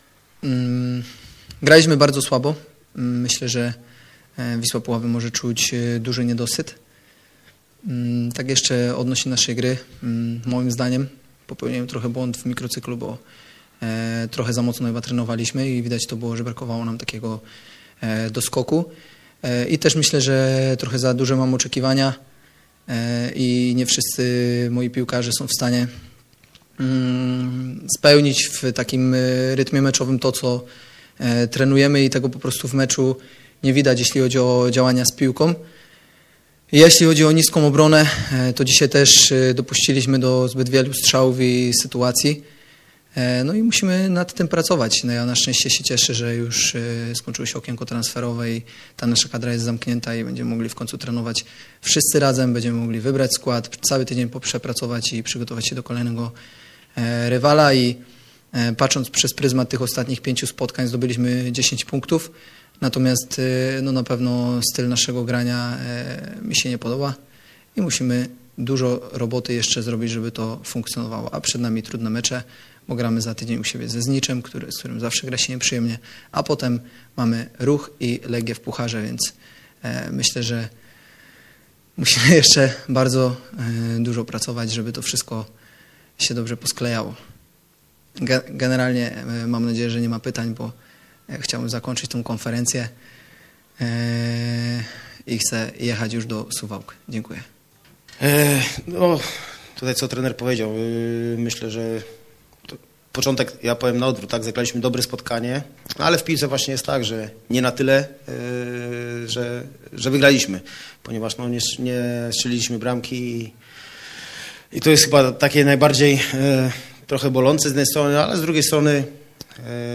Konferencja prasowa po meczu Wisła Puławy – Wigry Suwałki.